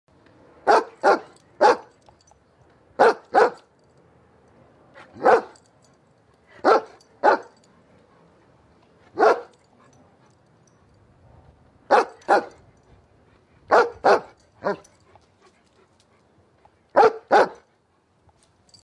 043345_dog Barking Efecto de Sonido Descargar
043345_dog Barking Botón de Sonido